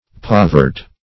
povert - definition of povert - synonyms, pronunciation, spelling from Free Dictionary Search Result for " povert" : The Collaborative International Dictionary of English v.0.48: Povert \Pov"ert\ (p[o^]v"[~e]rt), n. Poverty.